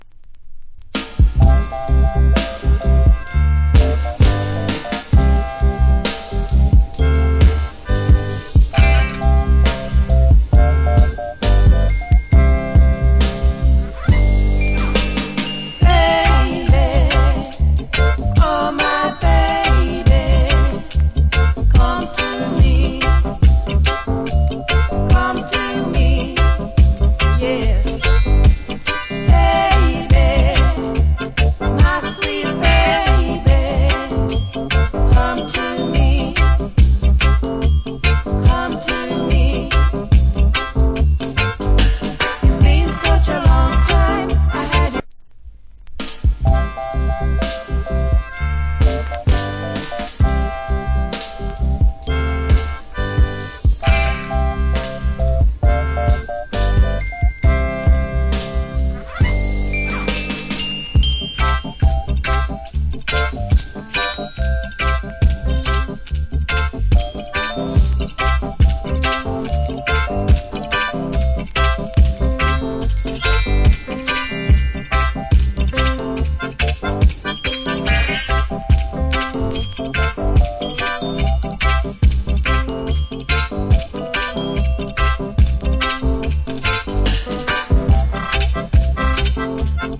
Nice Duet UK Lovers.